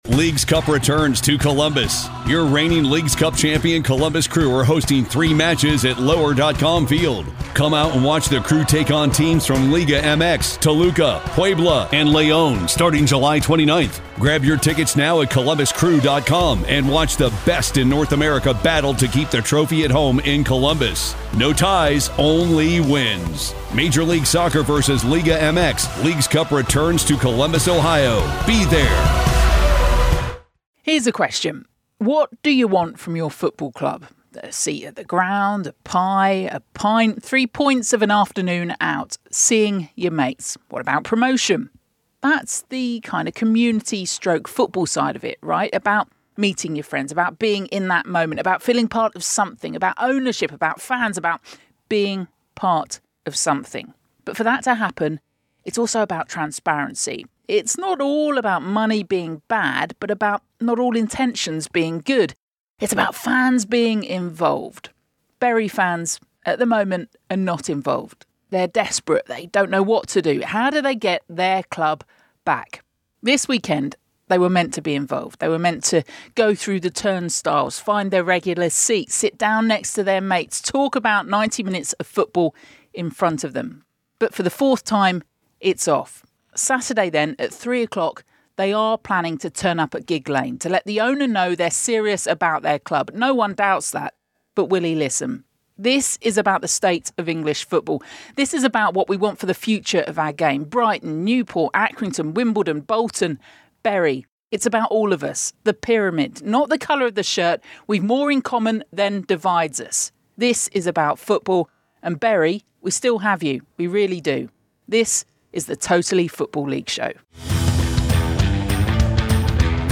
is joined in the studio